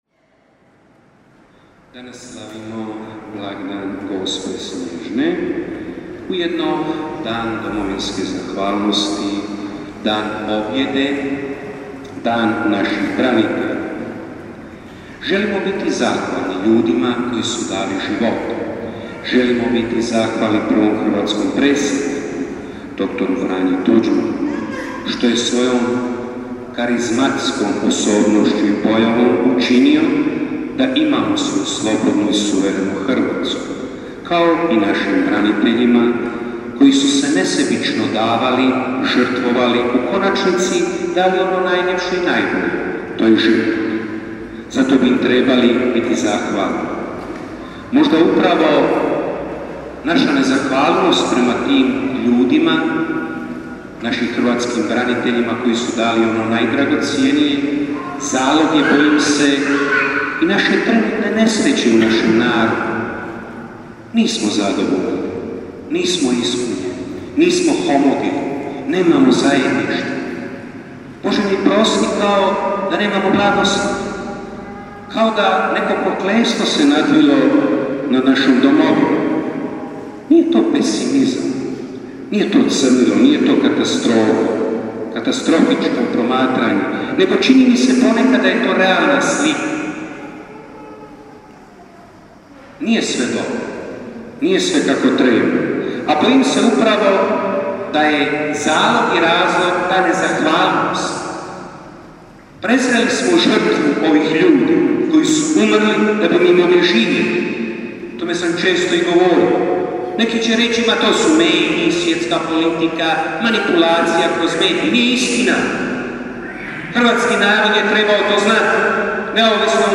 Svetkovina Gospe SNJEŽNE, mjesto događanja Župna Crkva (prije svete mise procesija).
PROPOVJED: